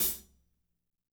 -16  HAT 1-R.wav